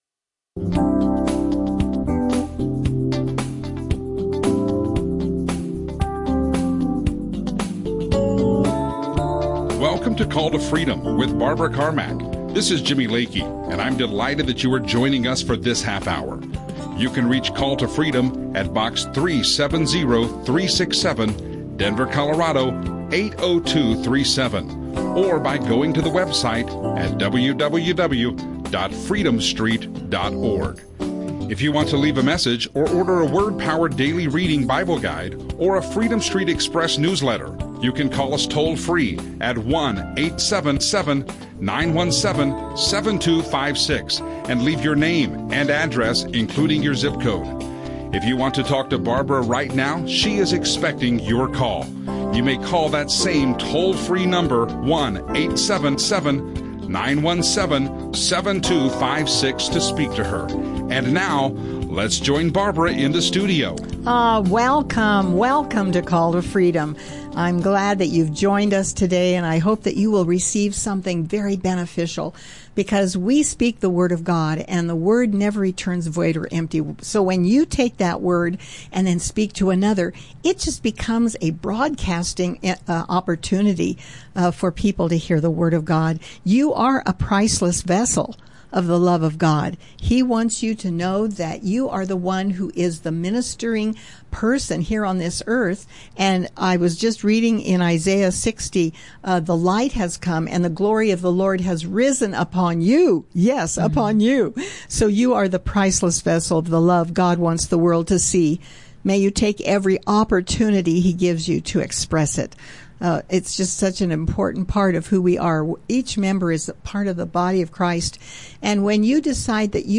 Ministry protection radio show